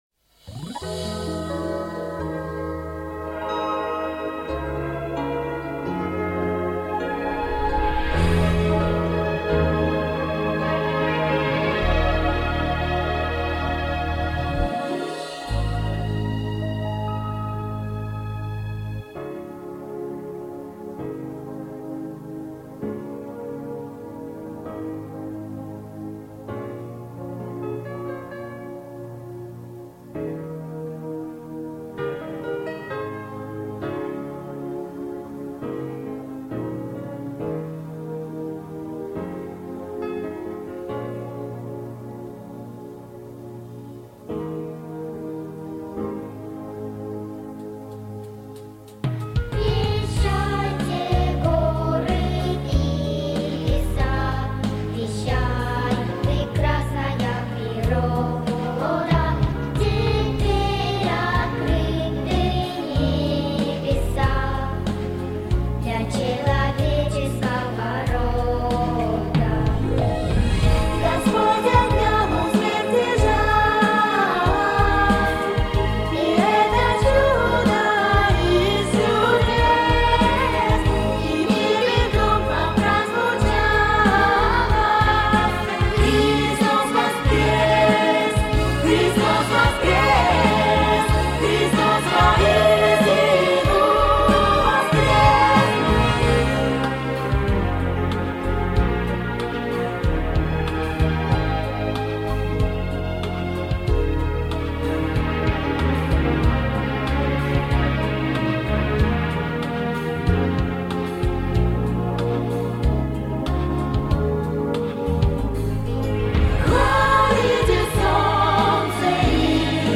10 Христос Воскрес (Минус)